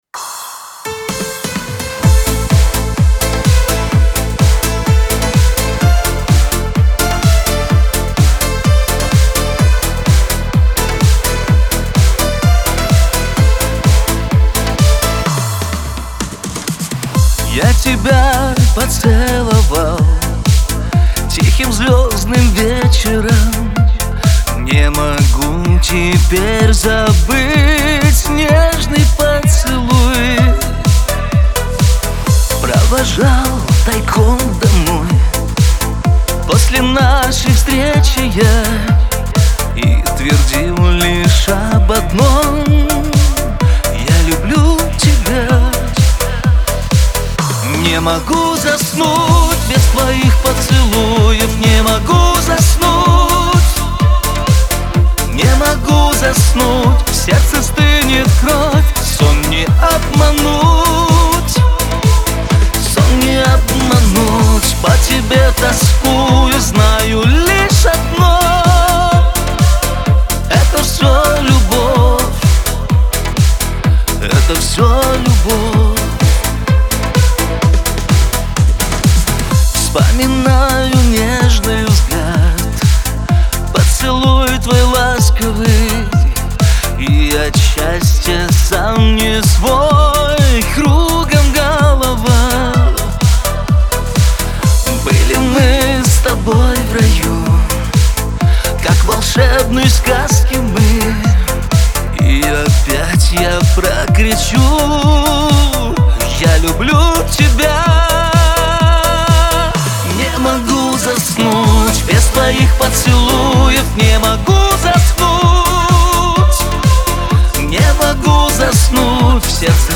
эстрада , pop , диско